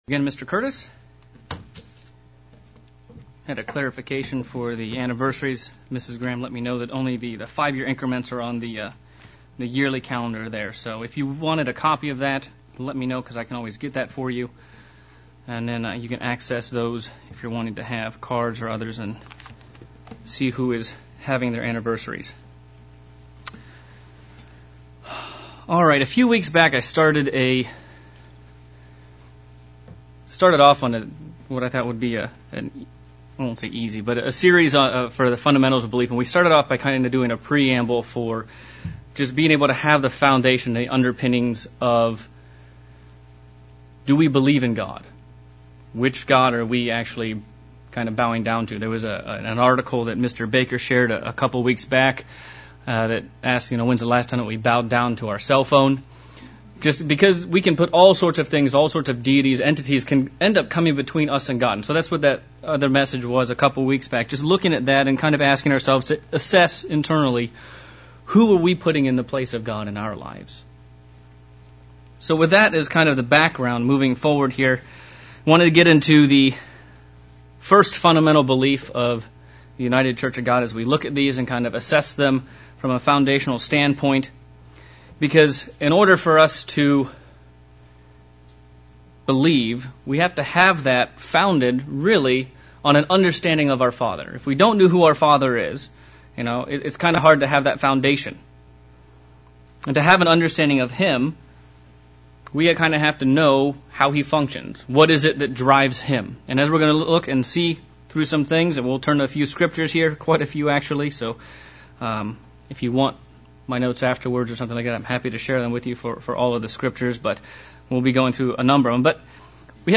Fundamentals of Belief sermon looking at the work of God the Father and how we need to get to know him better.